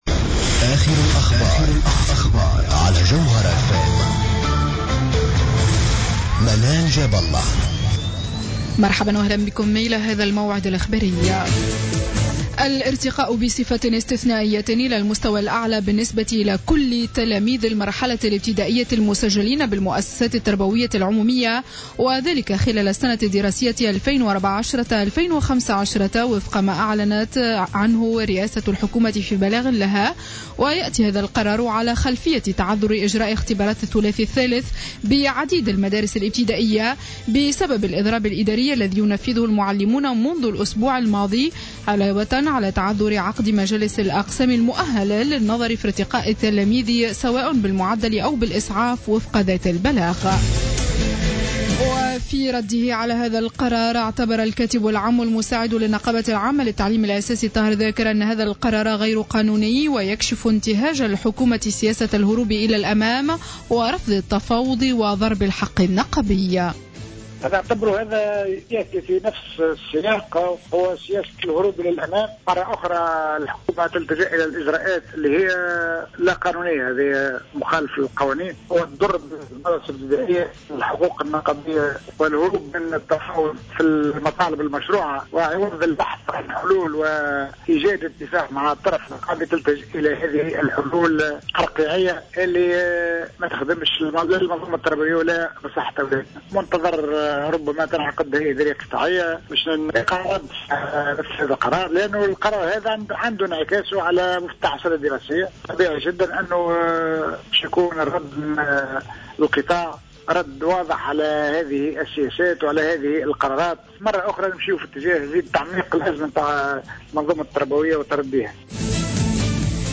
نشرة أخبار منتصف الليل ليوم الجمعة 12 جوان 2015